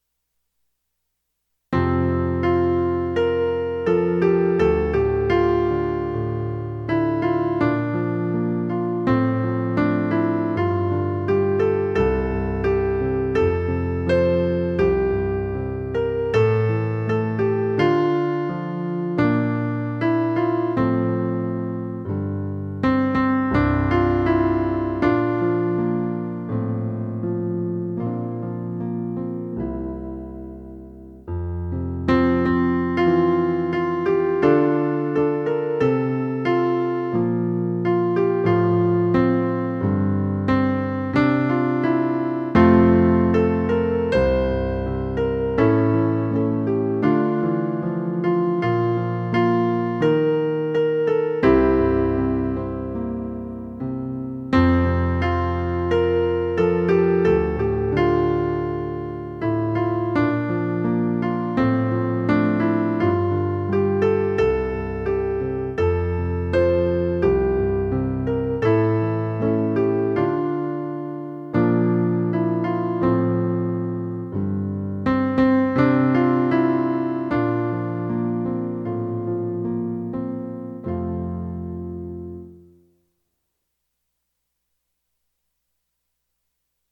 SetYourHeartOnTheHigherGifts_Women.mp3